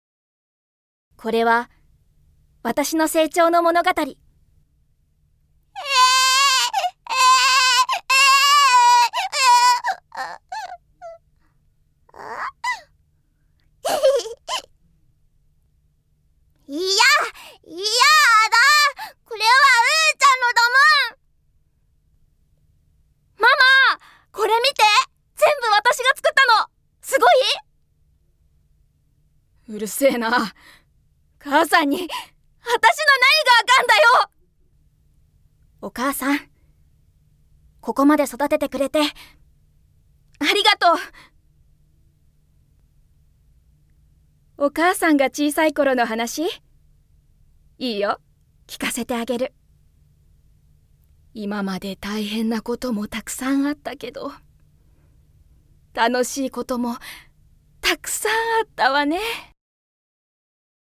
◆私の成長◆